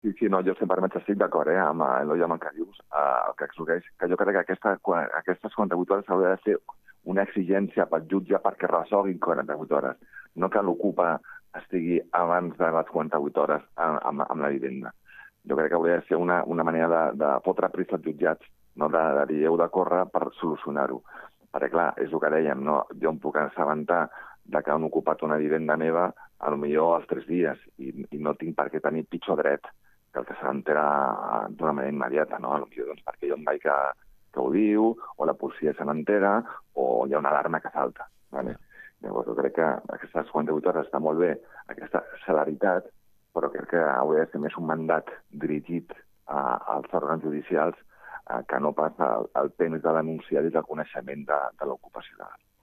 Entrevista a Eloi Cortés, regidor d'Acció Social de l'Ajuntament de Sabadell